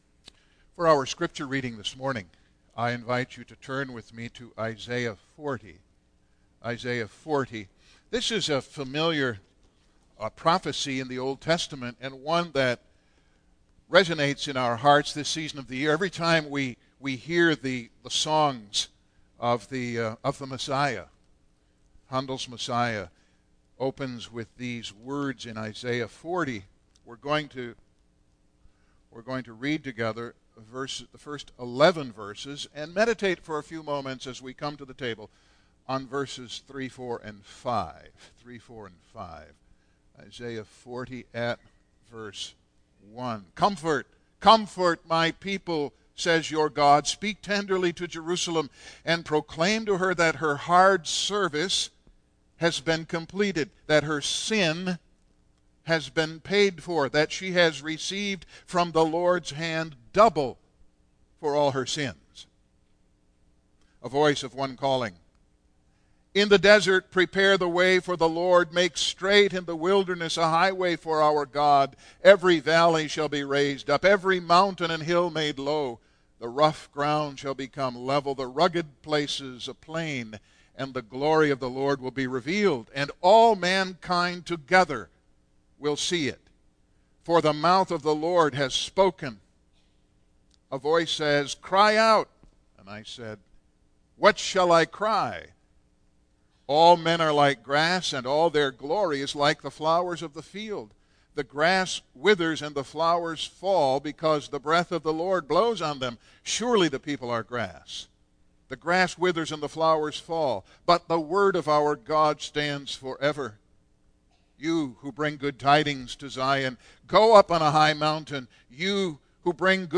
Single Sermons Passage: Isaiah 40:1-11 %todo_render% « Rachel’s Weeping for Her Children